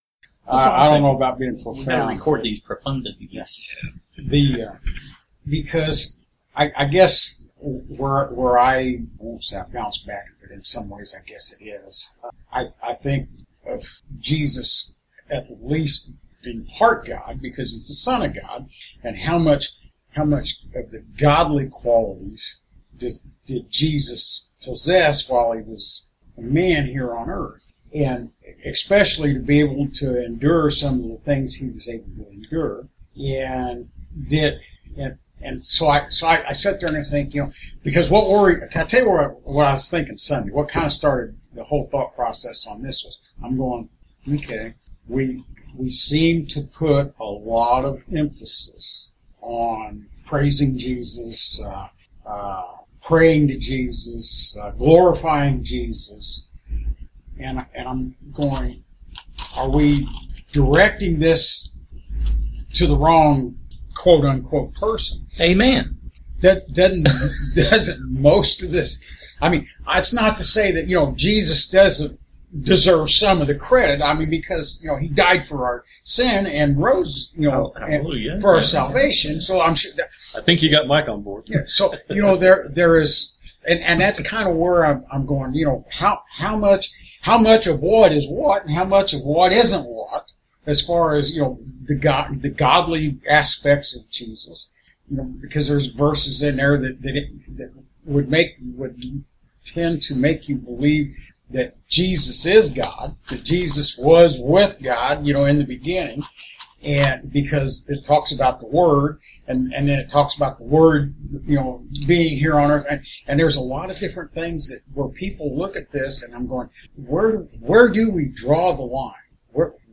When the recording opens we had already begun our journey down this path of exploration... A lively discussion focusing on many deep philosophical and theological ideas that many might find uncomfortable.